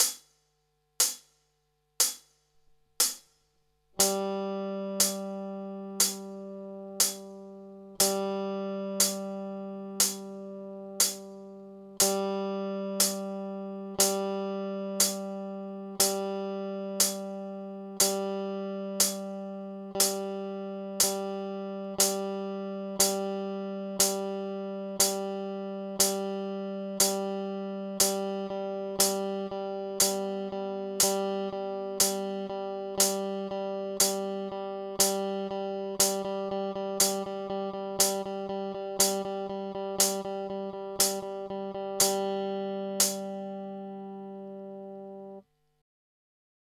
Tu si môžete vypočuť, ako znejú jednotlivé noty v tempe 60.